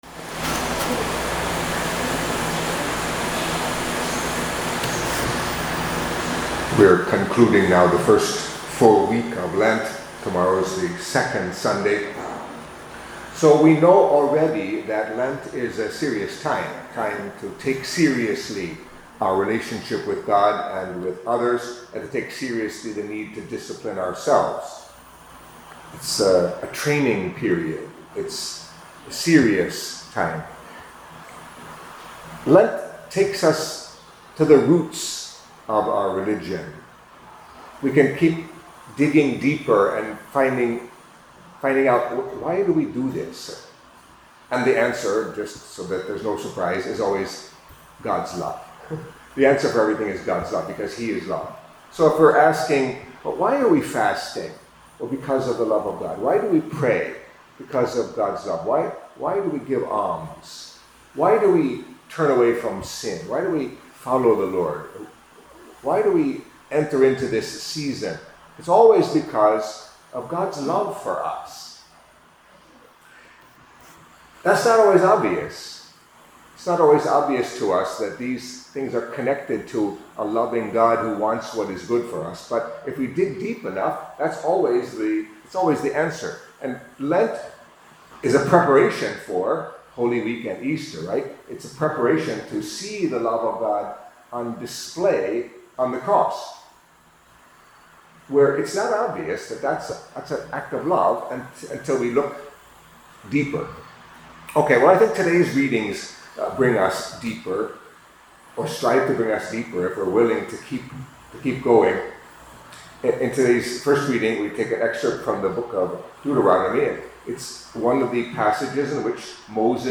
Catholic Mass homily for Saturday of the First Week of Lent